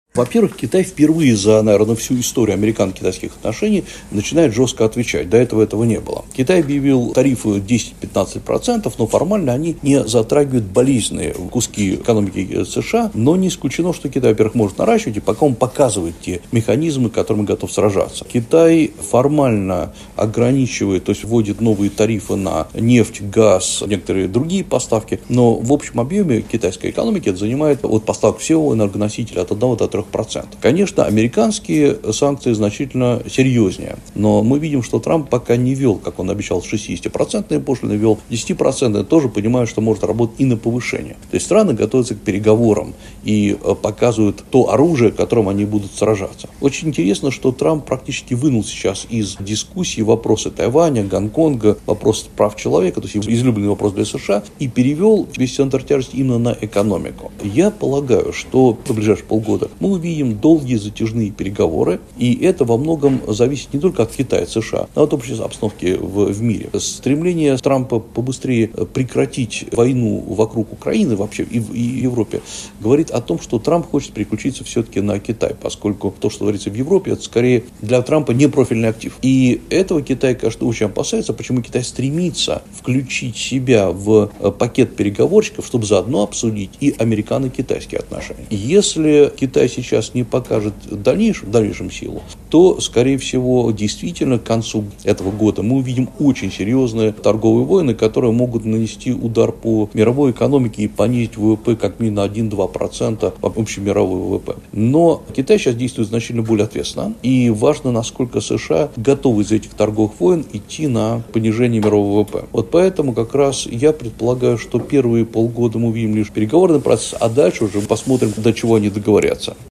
ГЛАВНАЯ > Актуальное интервью